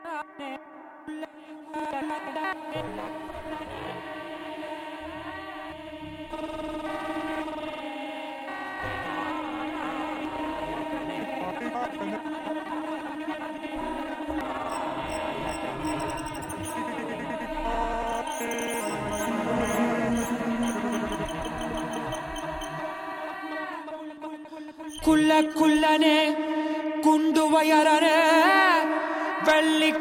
Musique audio
la chanteuse londonienne
le guitariste-producteur
gamelan contemporain javanais